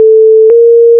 Vous reconnaitrez peut-�tre une seconde mineure.